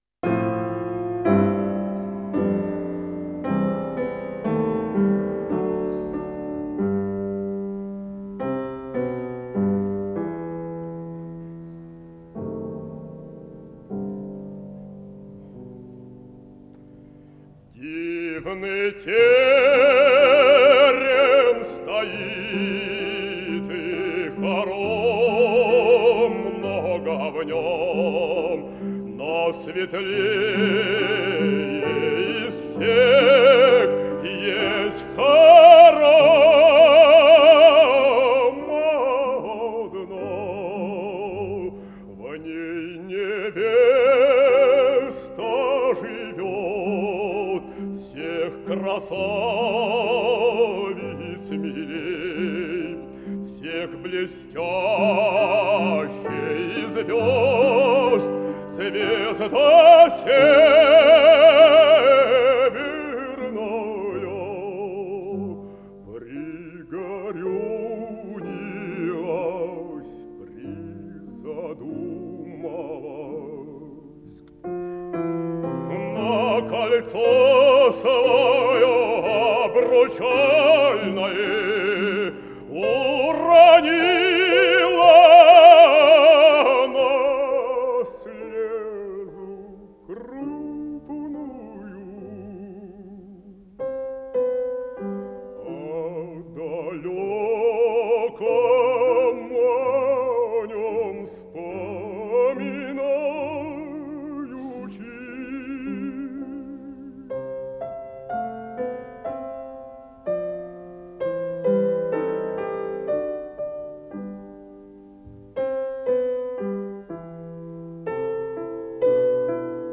Жанр: Вокал
ф-но
Большой зал Консерватории
баритон